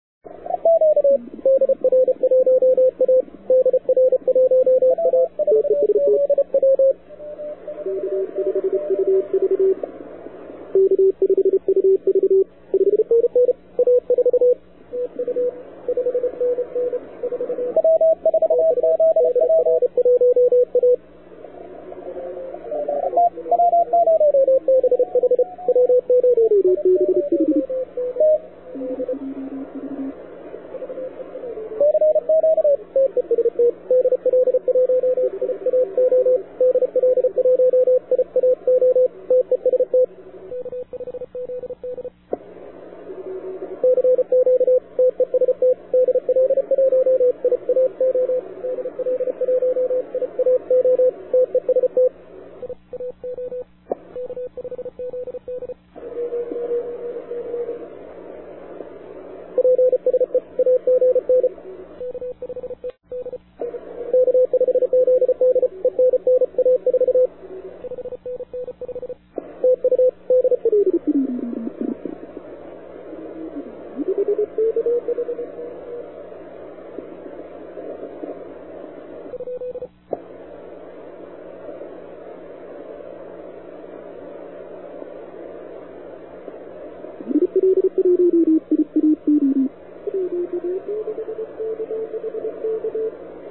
CQ WW CW - 40M (153KB), 15M (167KB) &
I never really felt loud with the siple INV V on 40m, but the recording right the beginning surprised!